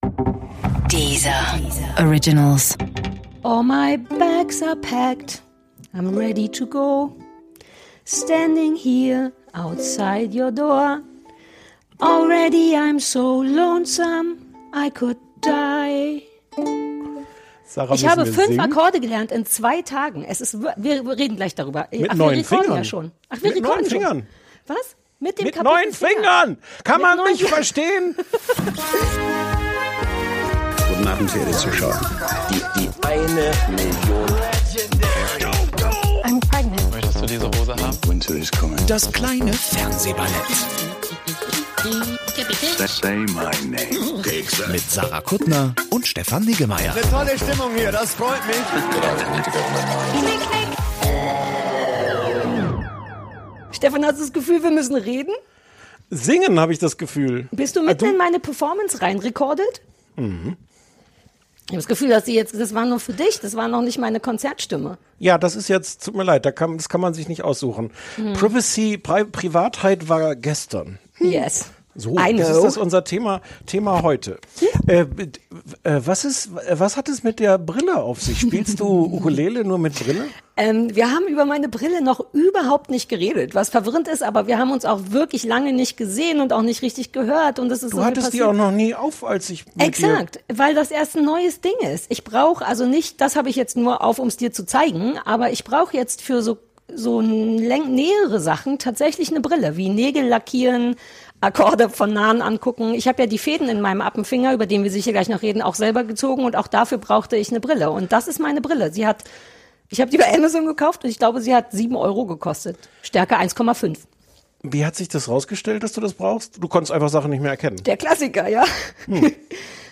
spielt auf der Ukulele